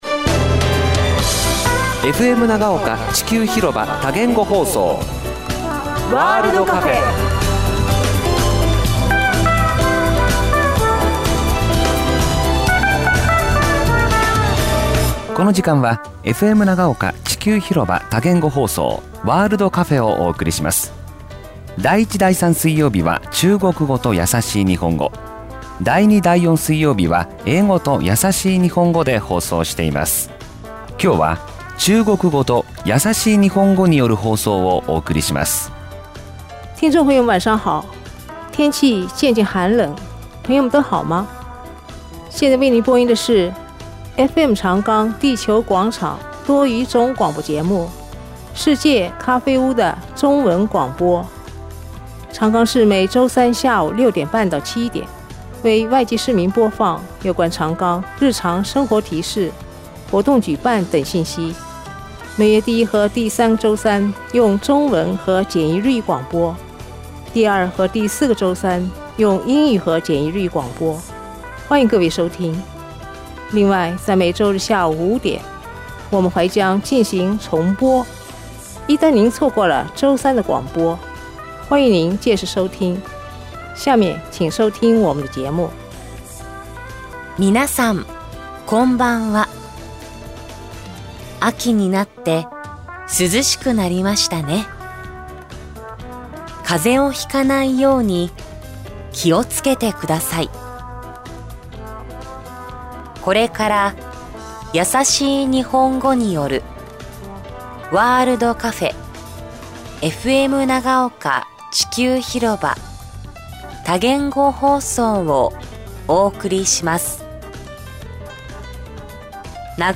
Multilingual broadcasts are being offered on FM Nagaoka (80.7 MHz) from 6:30 p.m. to 7:00 p.m. every Wednesday evening. The City of Nagaoka offers the World Cafe-FM Nagaoka-Chikyû Hiroba Multilingual Broadcasts for international residents in Nagaoka.